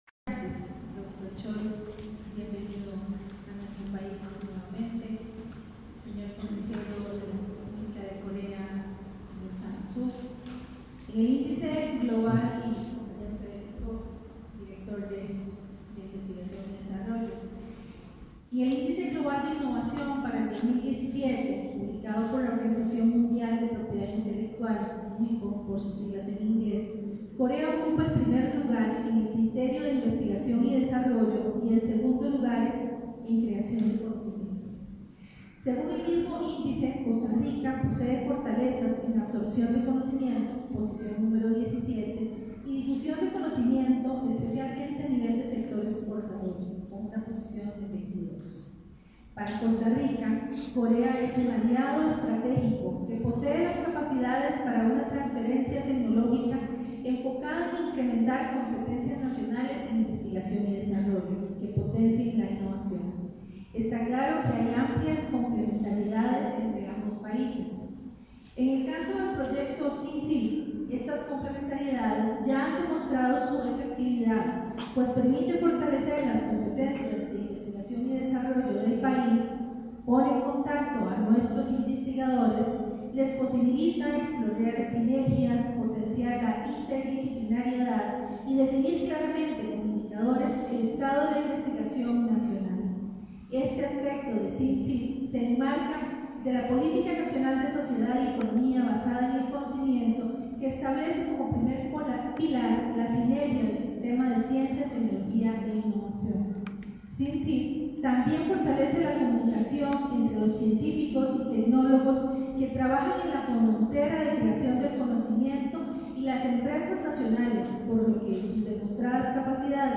Discurso de la ministra Carolina Vásquez en inauguración del Centro Comunitario Inteligente (CECI) de Cartago